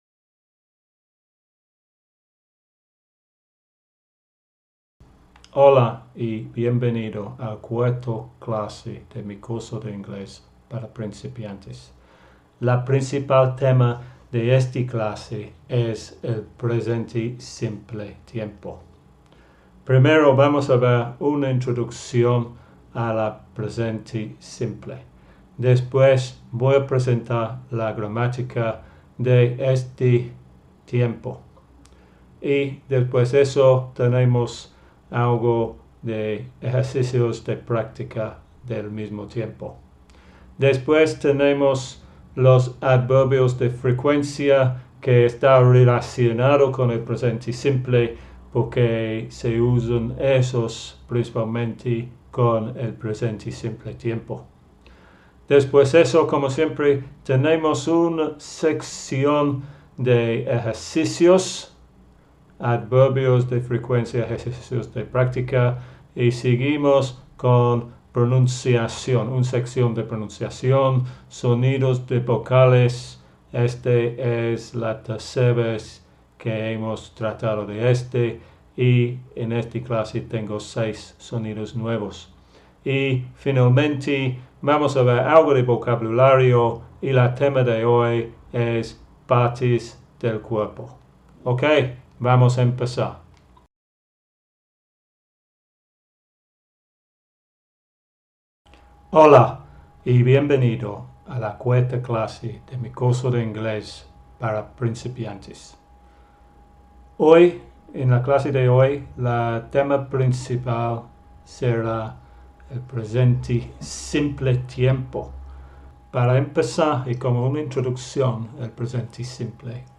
Curso de inglés para principiantes (A1 CEF), clase 06